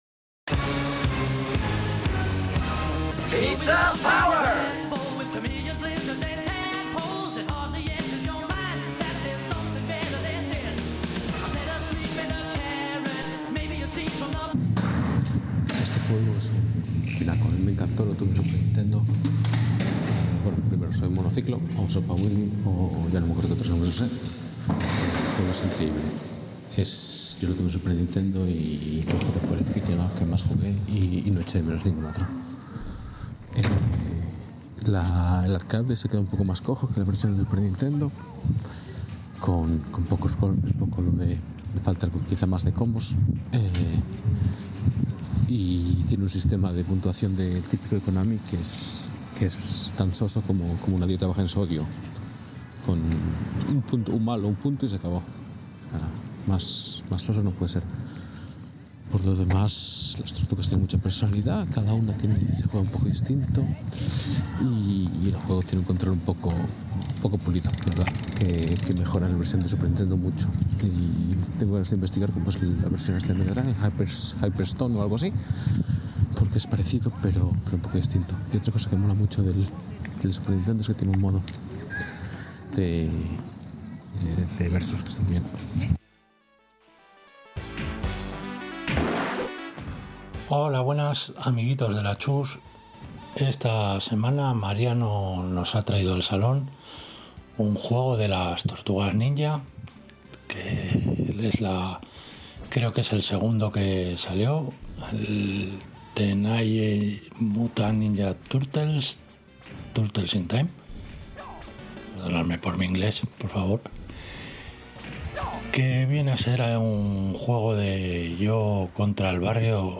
Que no sé si está grabado desde una cloaca, desde el interior de una tubería o directamente desde el más allá digital. Una experiencia sonora inmersiva. Demasiado inmersiva.